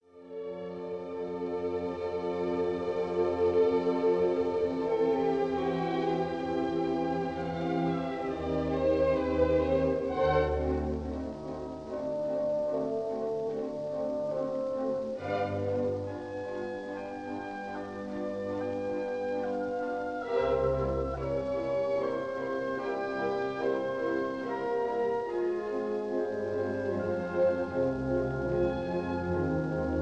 Adagio, 3/4 — Allegro spiritoso, 4/4
in a recording made in Londons Kingsway Hall in 1946